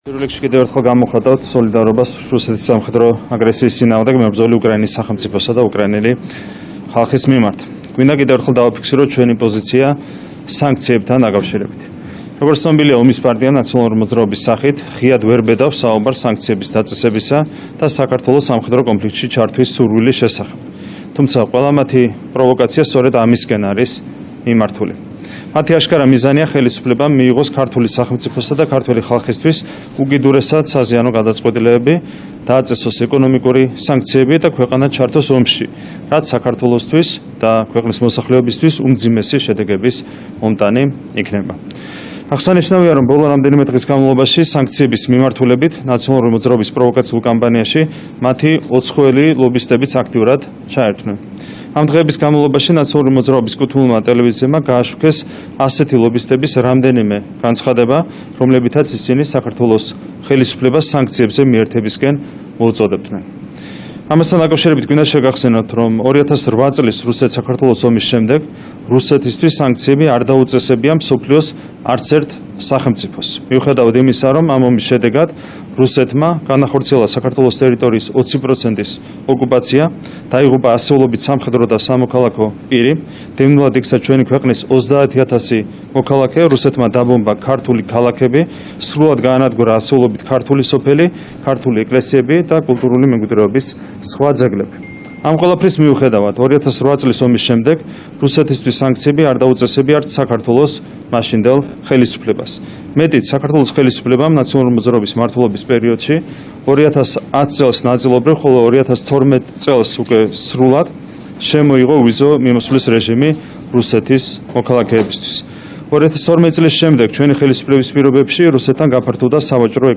ბრიფინგზე „ქართული ოცნების“ თავმჯდომარემ „ნაციონალურ მოძრაობას“ მიმართა და კიდევ ერთხელ მოუწოდა, „თუ სანქციების დაწესებას ემხრობიან, ფარული აგიტაციის, პროვოკაციების მოწყობისა და უცხოელი ლობისტების დაქირავების ნაცვლად, ამაზე ღიად ისაუბრონ“.
ირაკლი კობახიძის ხმა